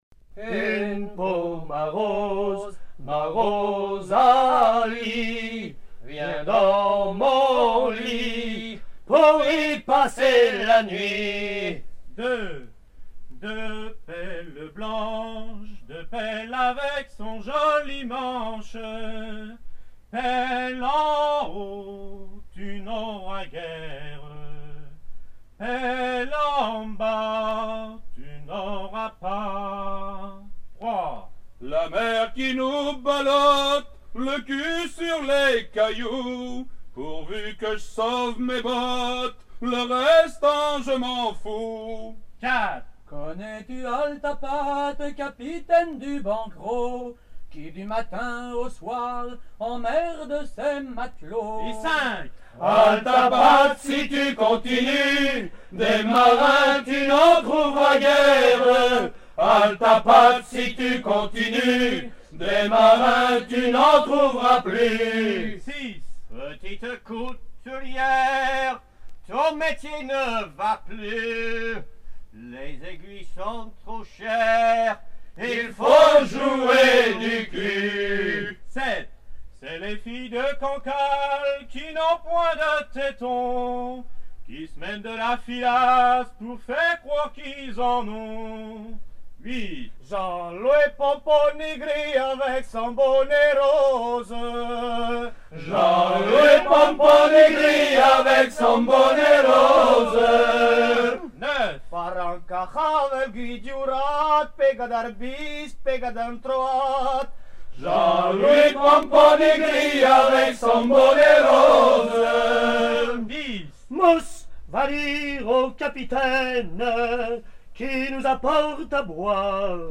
Suite de chant à curer les runs (creuser des tranchées dans le sel de la cale